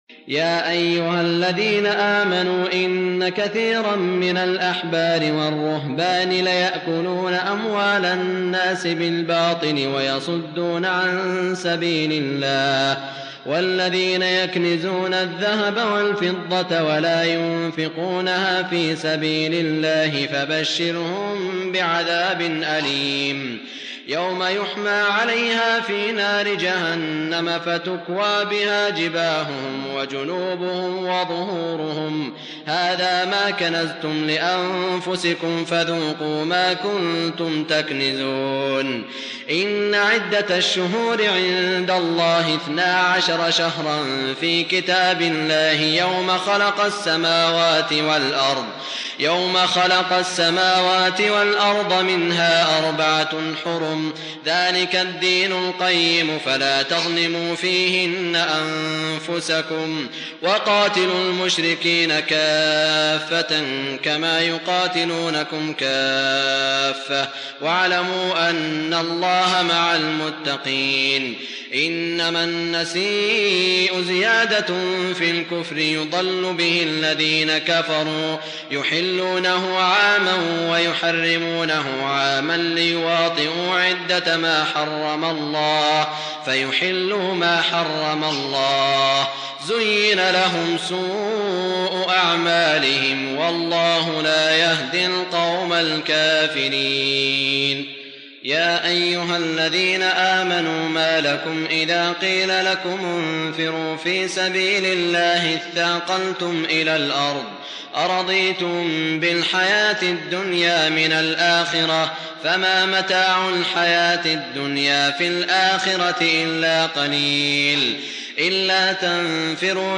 سورة التوبة ( ٣٤ - ٩٩) من عام ١٤٠٨ | بمدينة الرياض > الشيخ سعود الشريم تلاوات ليست من الحرم > تلاوات وجهود أئمة الحرم المكي خارج الحرم > المزيد - تلاوات الحرمين